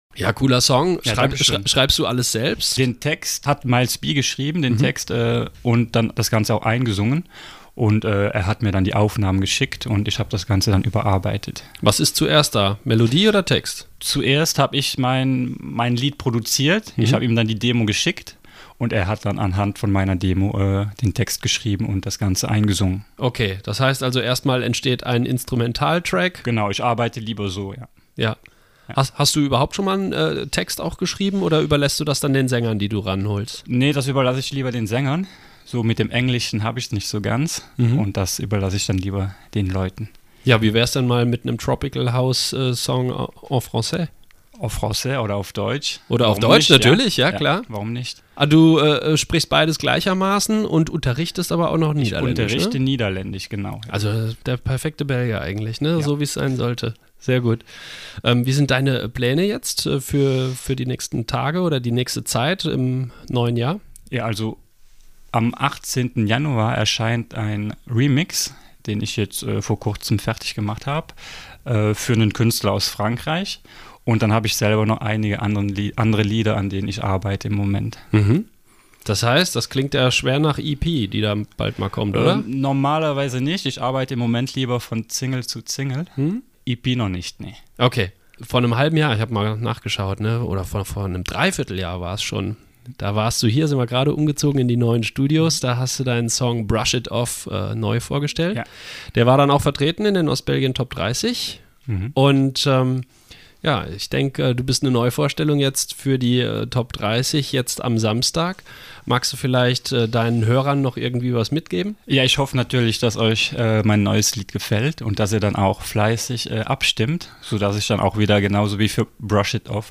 mit Studiogast